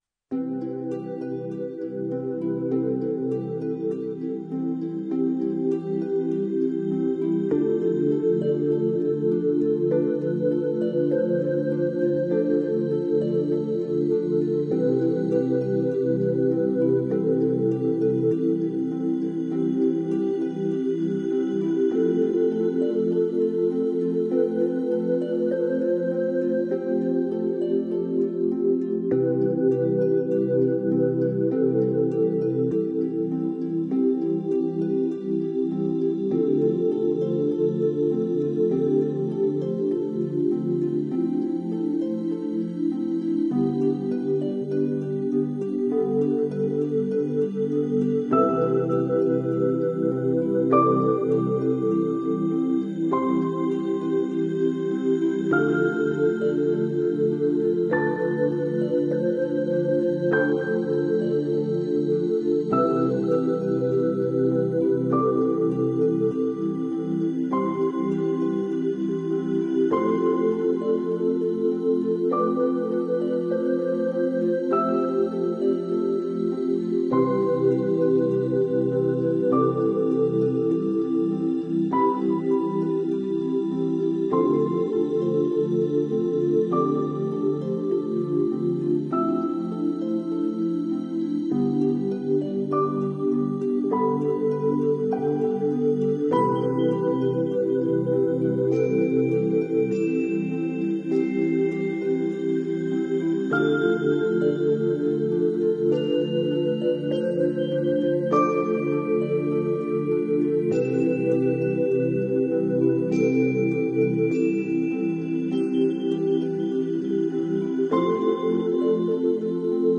meditation-relax.mp3